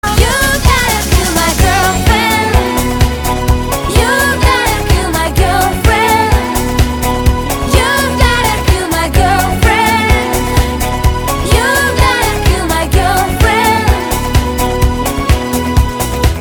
• Качество: 192, Stereo
поп
женский вокал
dance